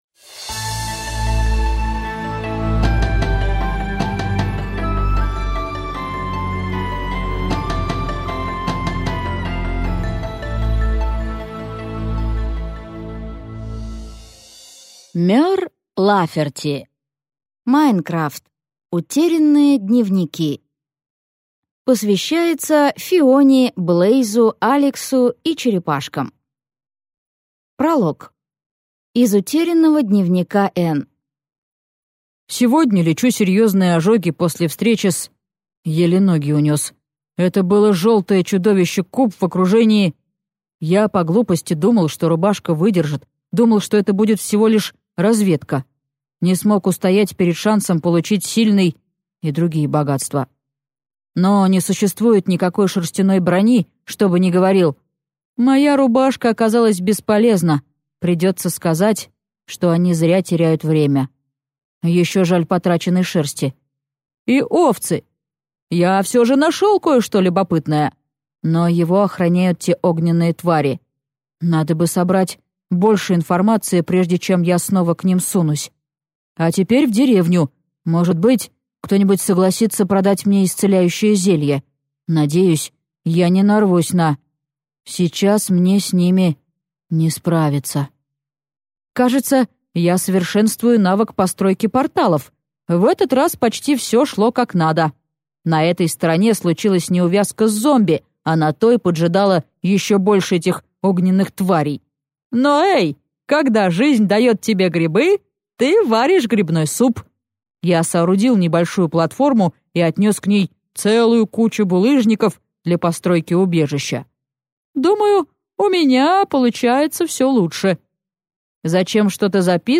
Аудиокнига Minecraft: Утерянные дневники | Библиотека аудиокниг